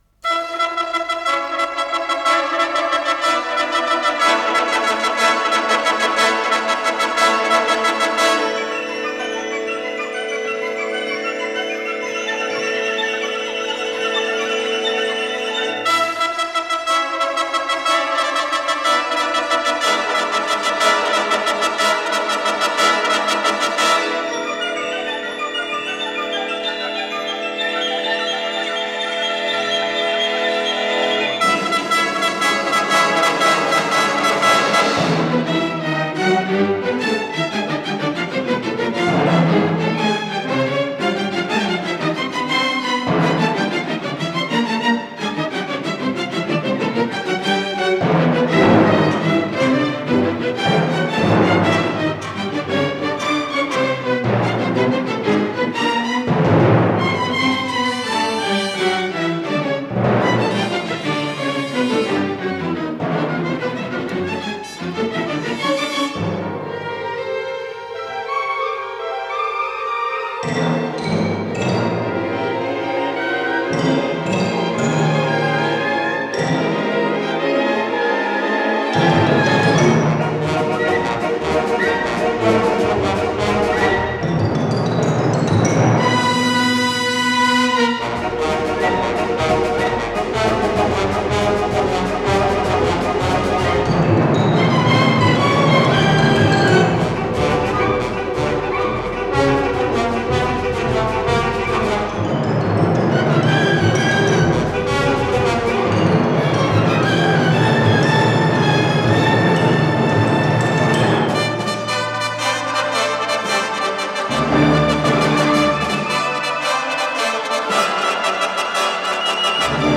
Ми минор, до мажор